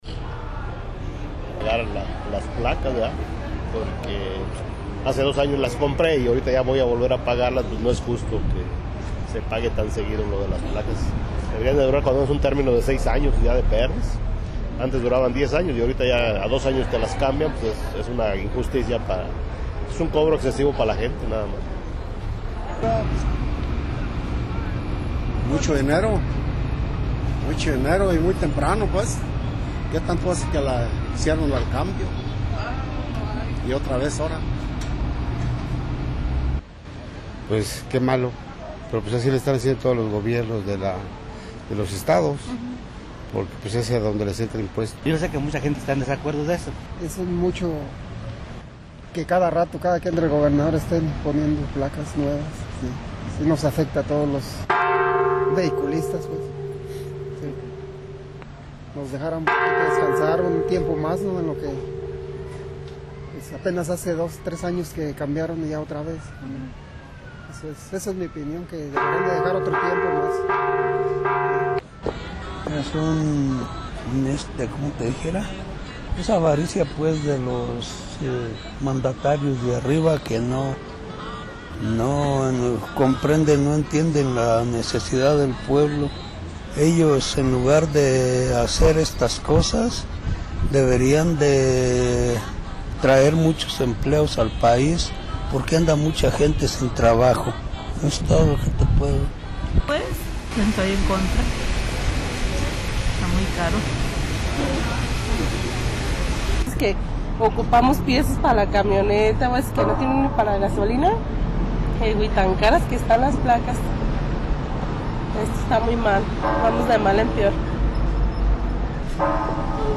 SONDEO SOBRE EL REEMPLACAMIENTO.
* Opiniones de la ciudadanía sobre este tema.